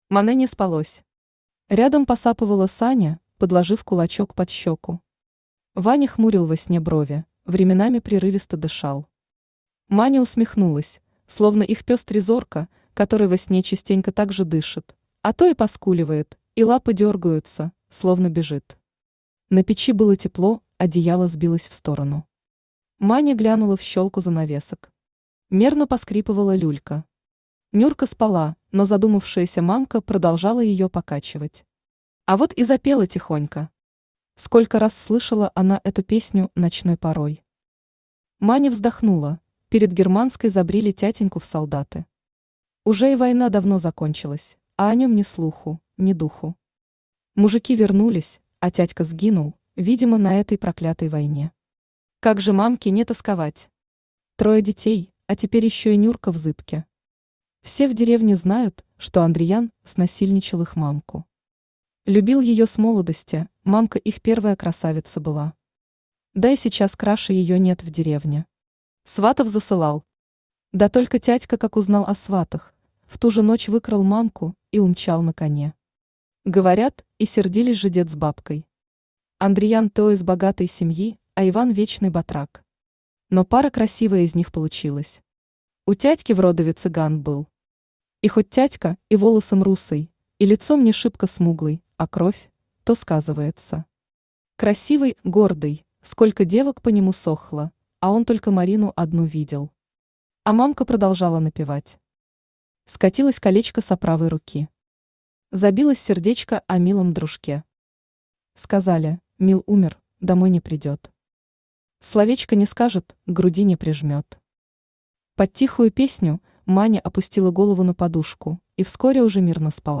Марина Автор Нина Захарина Читает аудиокнигу Авточтец ЛитРес.